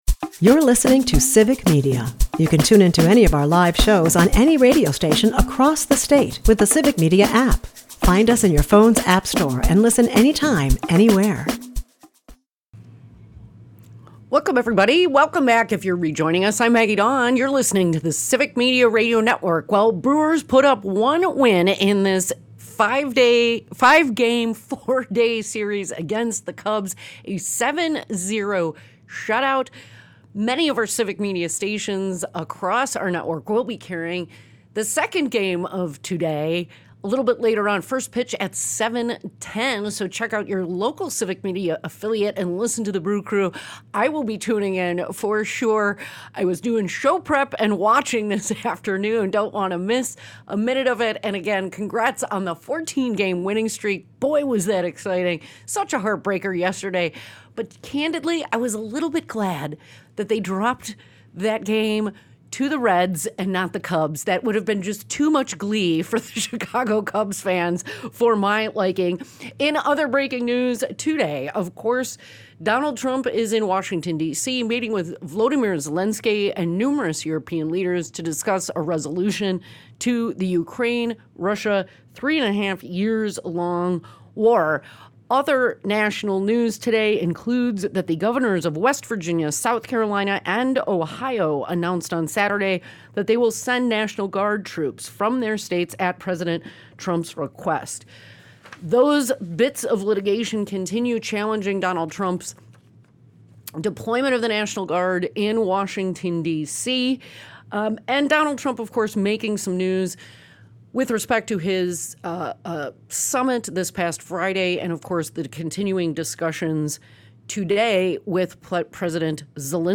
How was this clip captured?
The hour also spotlights Justice Rebecca Bradley’s costly judicial junkets, raising questions about partisan influence on the bench. With a lively audience weighing in, the conversation highlights the urgency — and the complexity — of protecting electoral integrity.